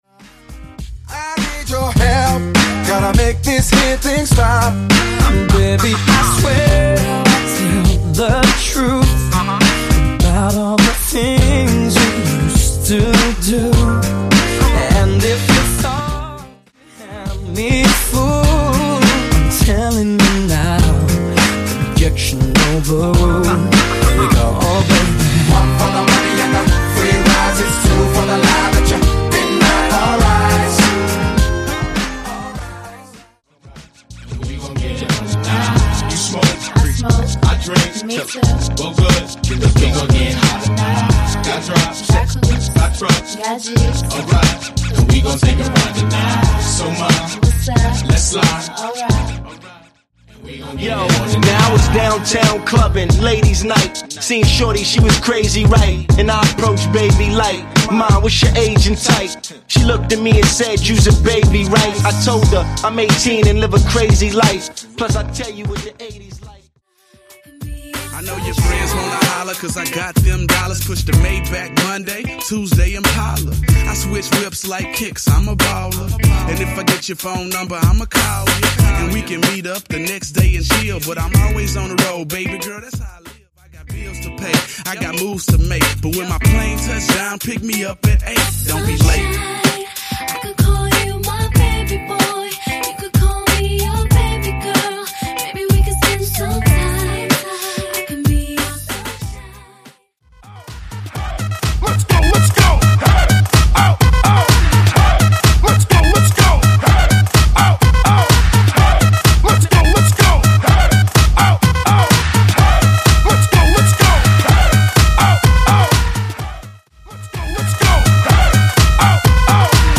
R & B